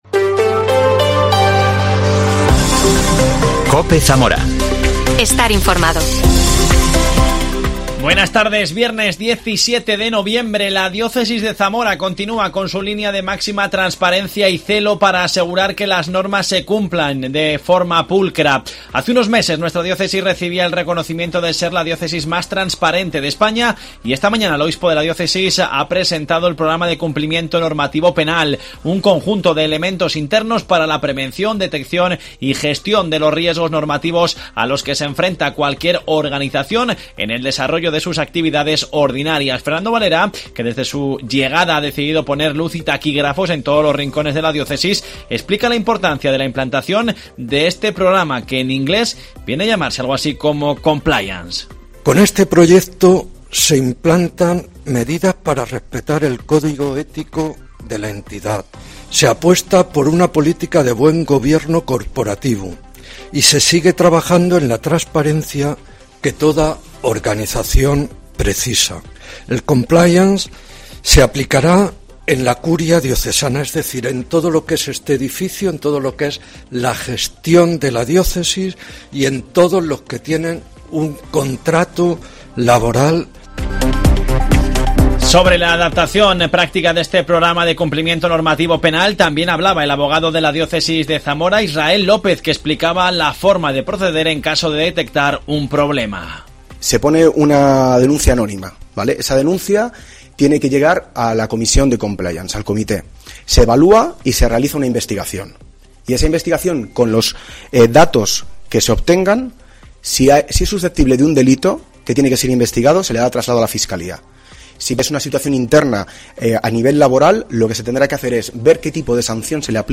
Fernando Valera, que desde su llegado ha decidido poner luz y taquígrafos en todos los rincones de la diócesis, explicaba en COPE Zamora la importancia de la implantación de este programa, que en inglés viene a llamarse 'compliance'